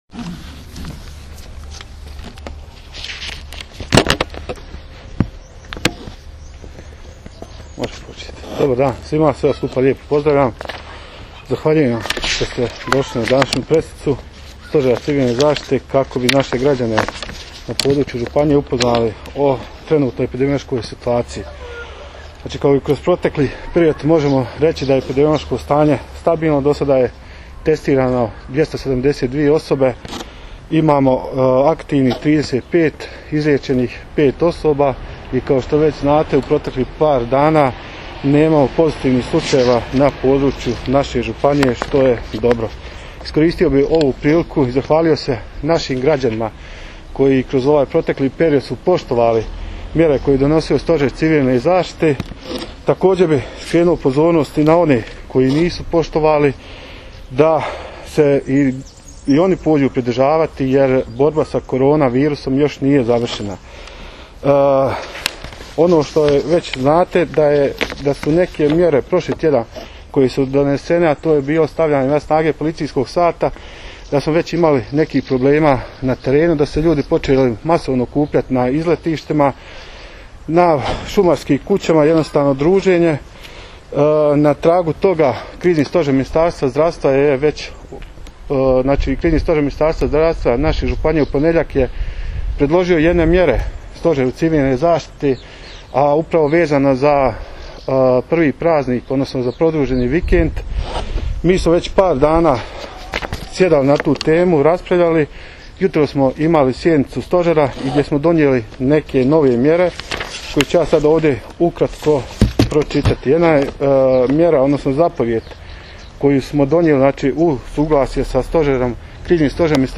Županijski stožer civilne zaštite održao konferenciju za medije
Nakon održane 17. izvanredne sjednice Županijskog stožera civilne zaštite na kojoj su donesene nove Zapovijedi na području Hercegbosanske županije članovi Stožera održali su konferenciju za medije. Na konferenciji su iznesene najnovije informacije o broju testiranih, oboljelih i izliječenih osoba u županiji. Također, predstavljene su i nove Zapovijedi koje je na današnjoj sjednici donio Stožer civilne zaštite Hercegbosanske županije.